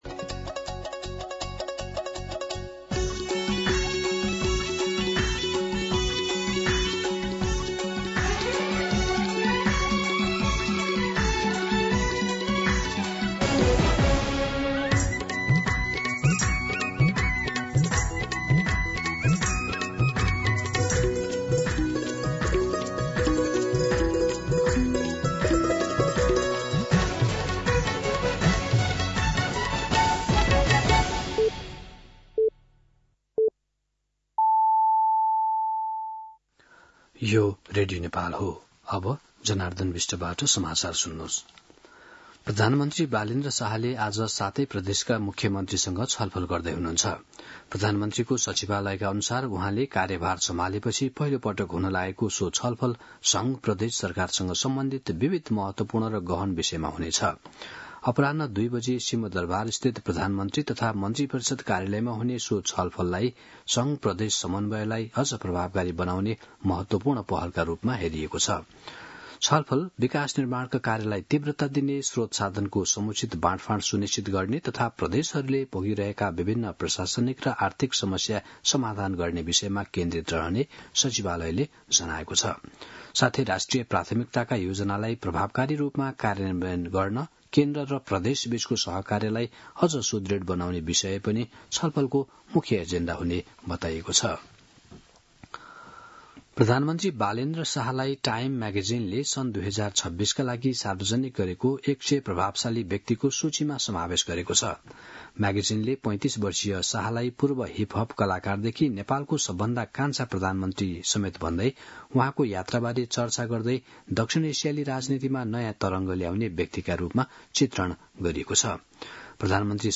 दिउँसो १ बजेको नेपाली समाचार : ३ वैशाख , २०८३
1-pm-Nepali-News.mp3